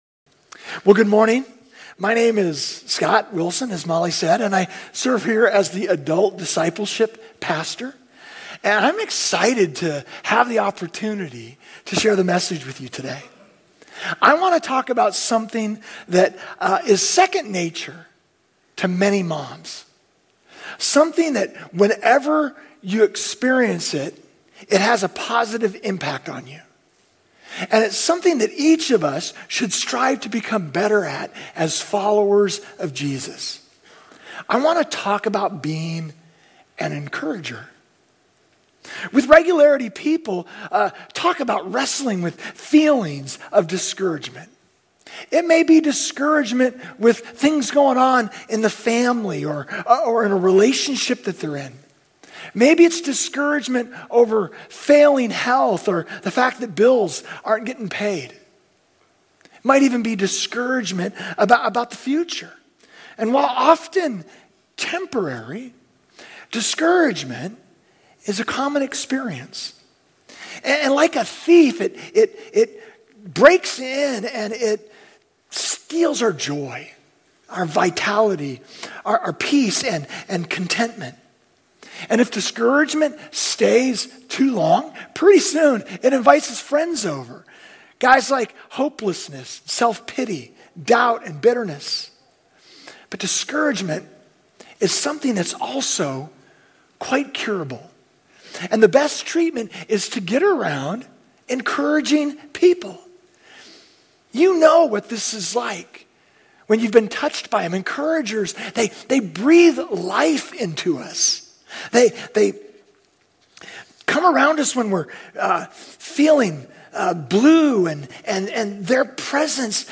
Grace Community Church Old Jacksonville Campus Sermons MOTHER'S DAY May 13 2024 | 00:33:08 1x 00:00 / 00:33:08 Subscribe Share RSS Feed Share Link Embed